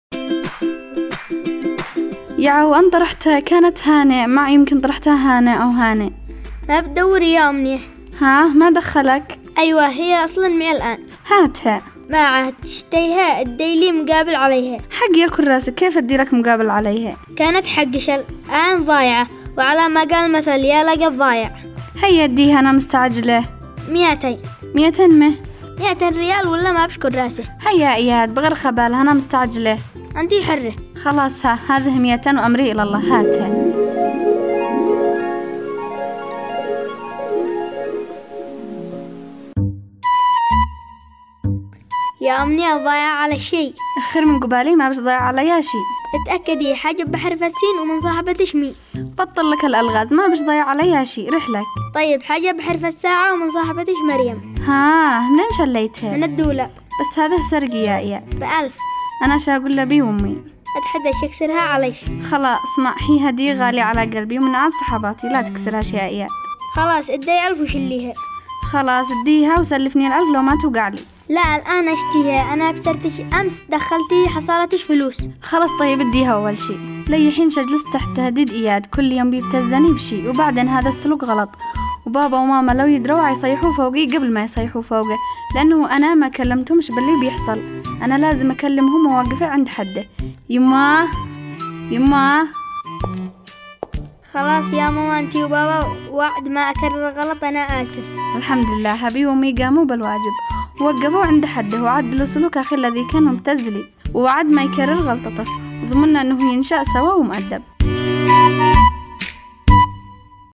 برنامج انا واياد  تستمعون إليه عبر إذاعة صغارنا كل احد الساعة 1:00 ظهرا
حلقات دراميه تناقش مواضيع تخص الطفل والوالدين